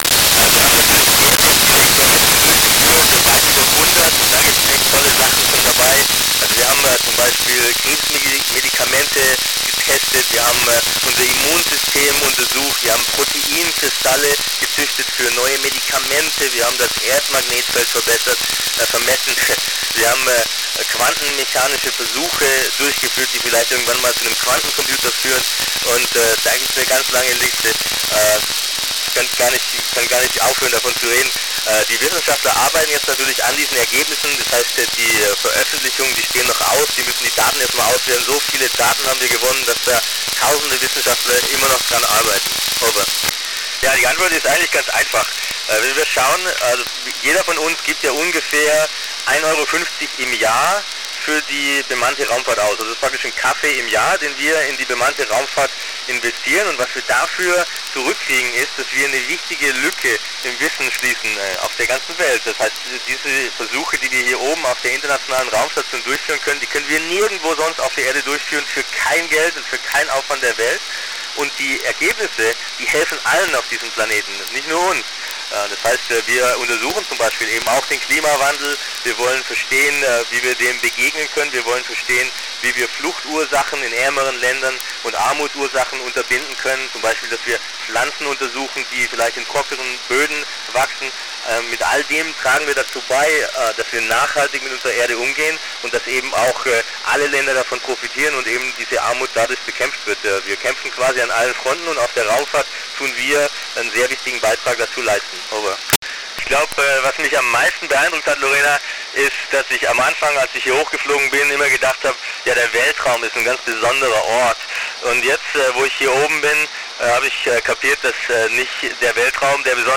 Nagranie audio łączności międzynarodowej stacji kosmicznej 16 października 2018 (po niemiecku)